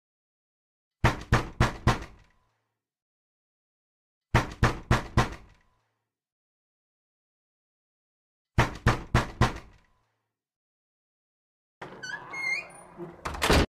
เสียงเคาะประตู3ครั้ง
เสียงเคาะประตู ก๊อก ก๊อก ก๊อก (เบา ๆ) ริงโทนที่กวนส้นตีนที่สุด
am-thanh-go-cua-3-lan-www_tiengdong_com.mp3